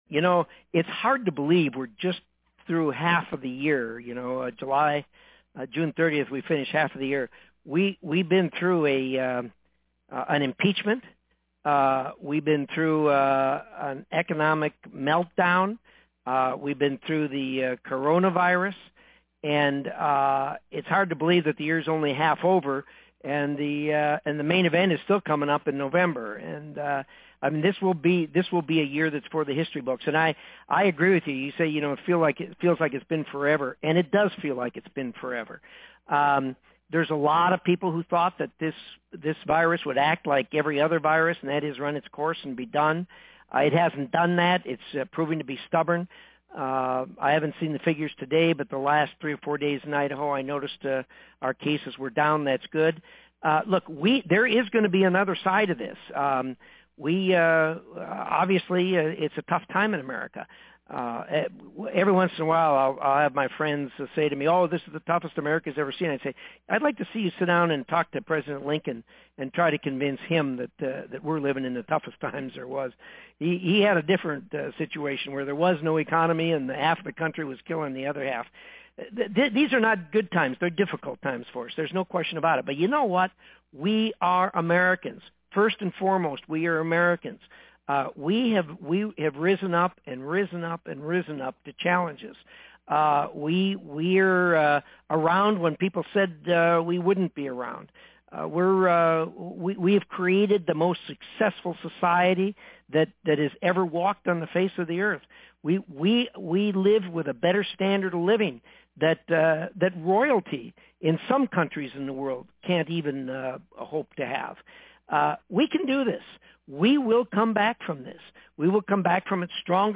WASHINGTON – On Tuesday, U.S. Senator Jim Risch (R-Idaho) hosted a live telephone-town hall to speak with Idahoans about the ongoing COVID-19 pandemic and current events in Washington, D.C.
Senator Risch speaks to constituents during August 4th tele-town hall